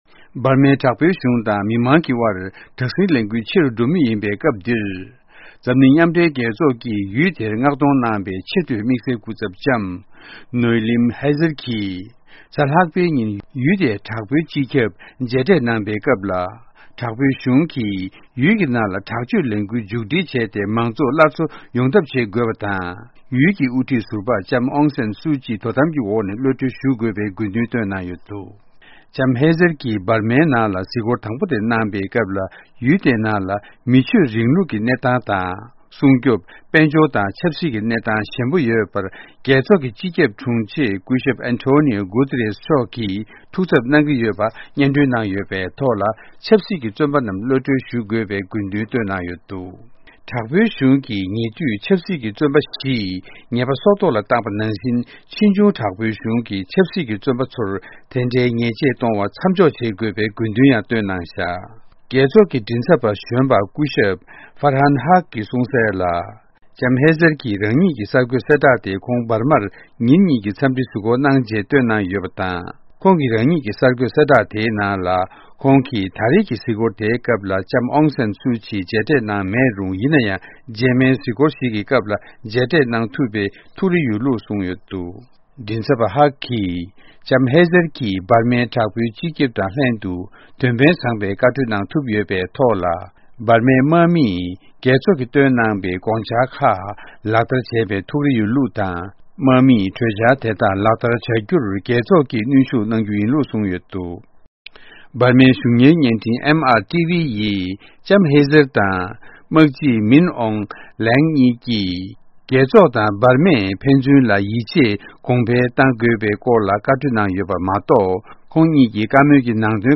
སྙན་སྒྲོན་ཞུ་ཡི་རེད།།